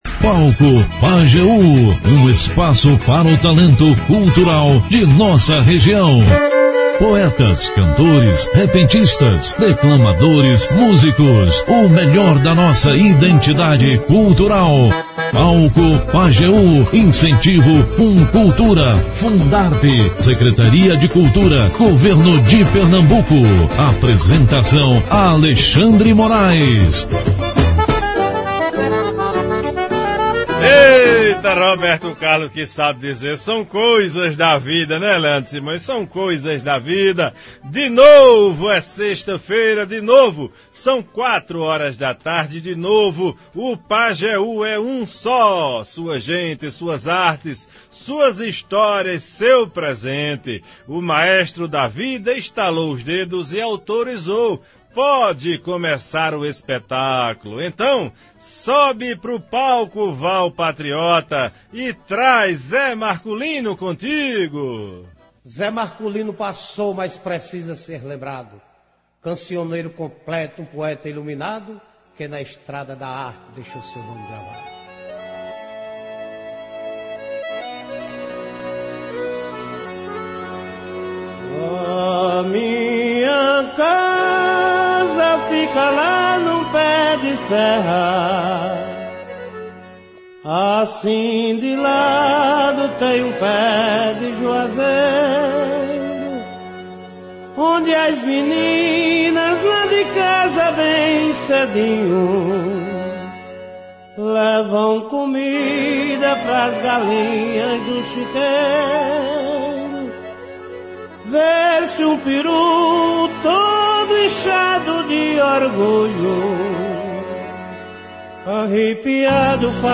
Os dois são poetas, repentistas, compositores, cantores e artesãos.
Confira abaixo como a integra do programa em vídeo ou áudio: O Palco Pajeú é um espaço para o talento cultural da região, com poetas, cantores, repentistas, declamadores, músicos e o melhor da identidade cultural do sertanejo.